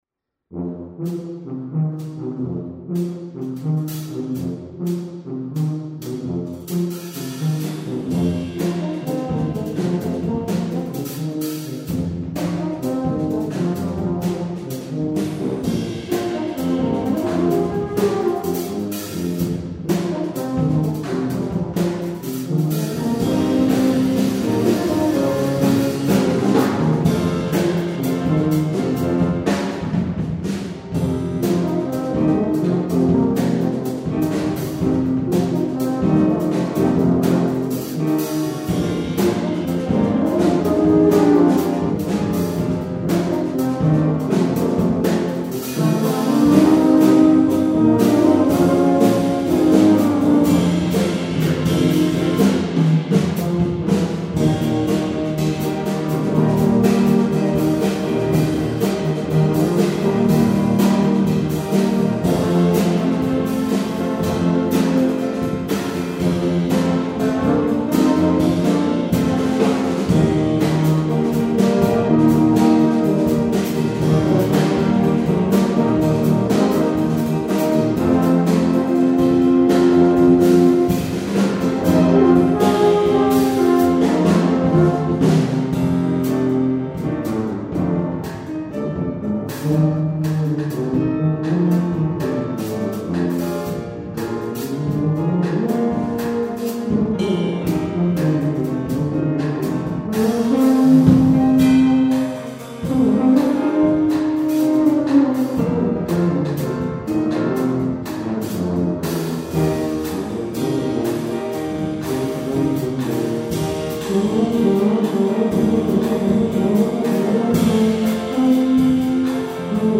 For Tuba Quartet (EETT)
with Piano and Drum Set.